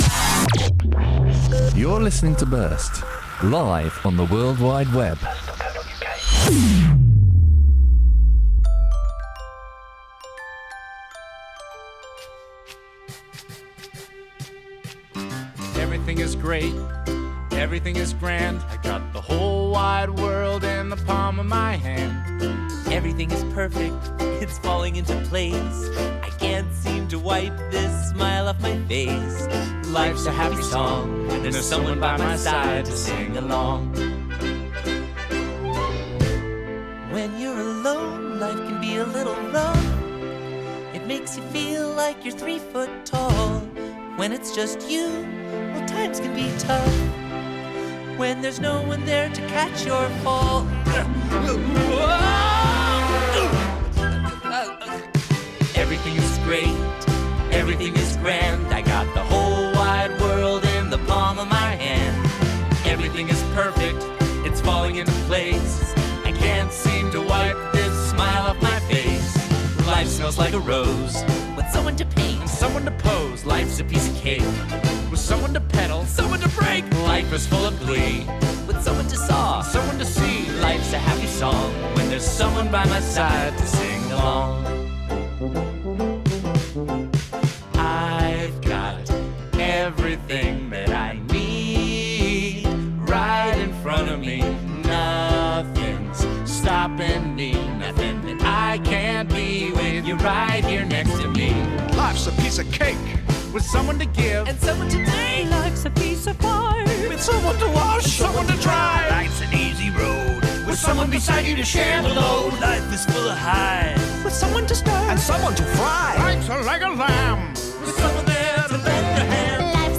TFT-Happy-Hour-programme.mp3